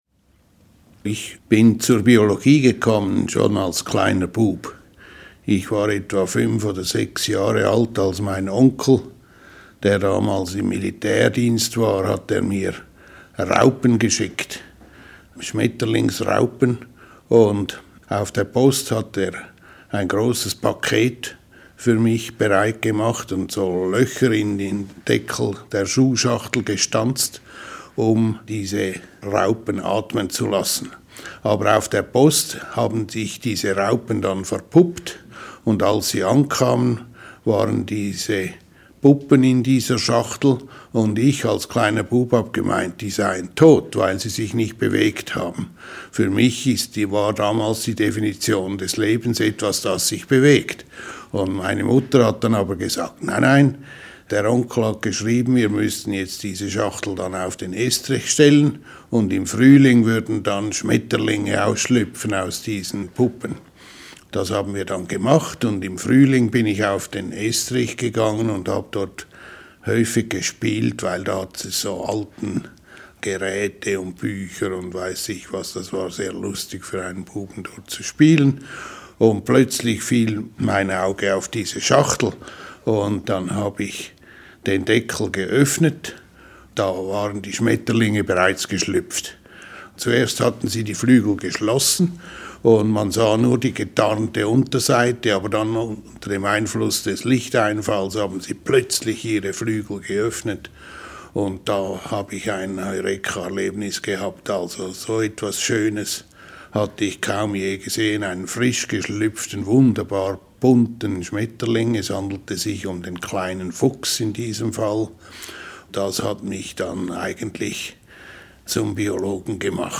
Walter J. Gehring erzählt eine genetische Theorie der Entwicklung